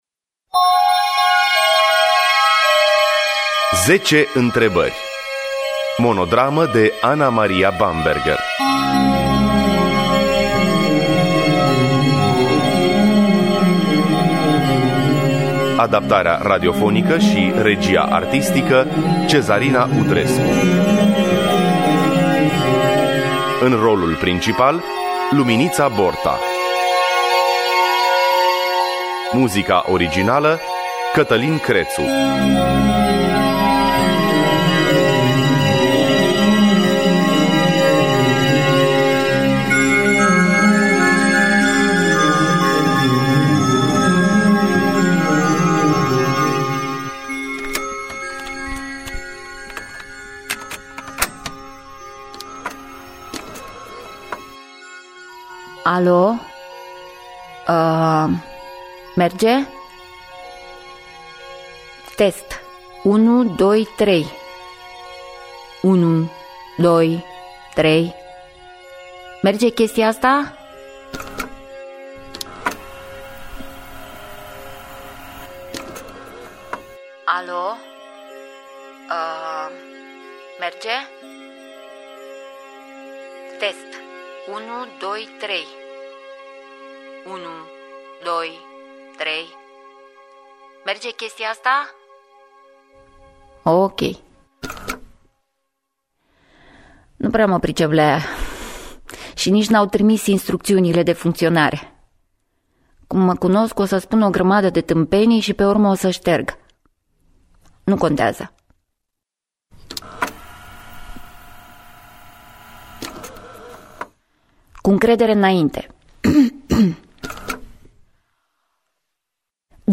10 Intrebari Monodrama De Ana-Maria Bamberger.mp3